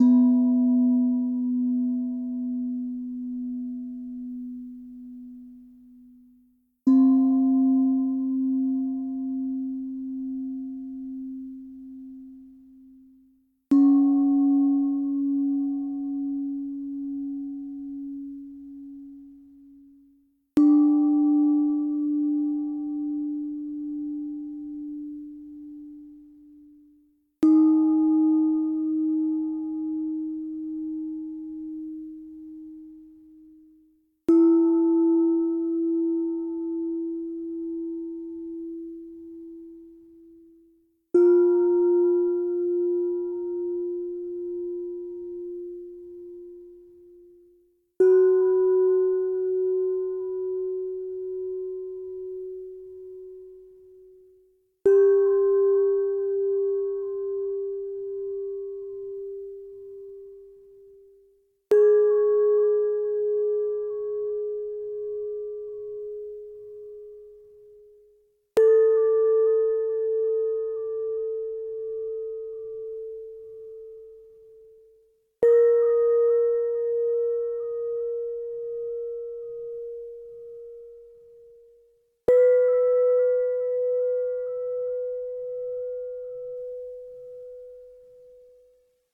Zen Gong (Scale B)
ambient B bell bong bowl chilled ding dong sound effect free sound royalty free Nature